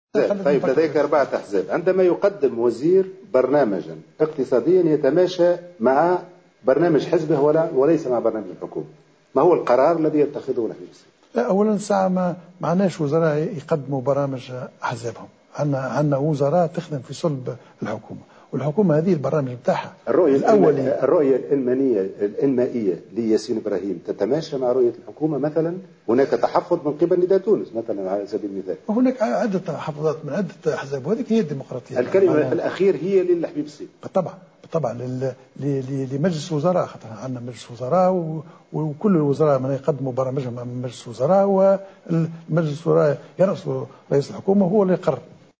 وأضاف الحبيب الصيد أن الحكومة ليس فيها وزراء يقدمون برامج اقتصادية باسم احزابهم وذلك في رده على سؤال وجه له في حوار حصري مع قناة "فرانس 24" حول البرنامج الذي قدمه ياسين ابراهيم والذي لايتماهي مع البرنامج الذي قدمته الحكومة.